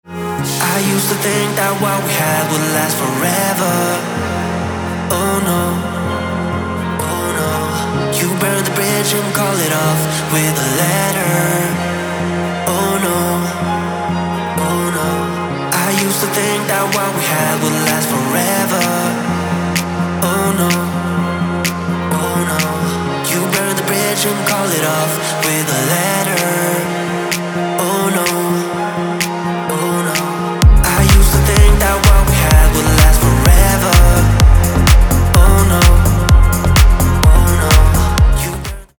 Progressive House / Organic House